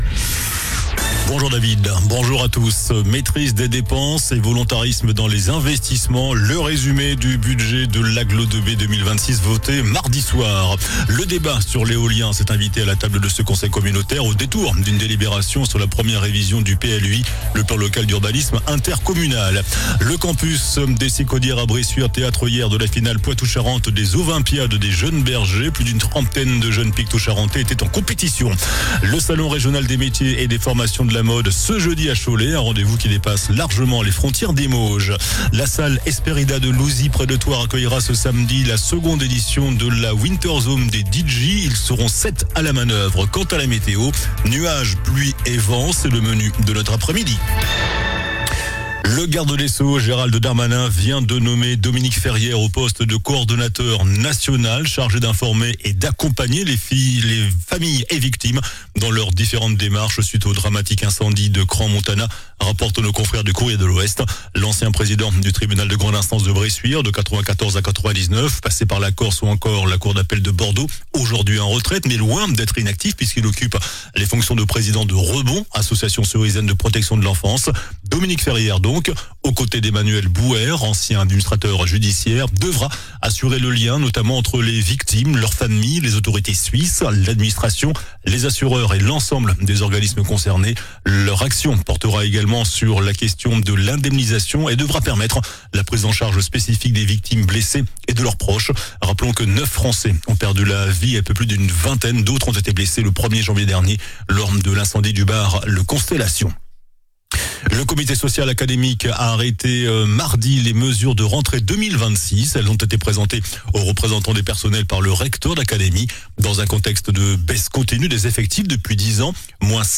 JOURNAL DU JEUDI 05 FEVRIER ( MIDI )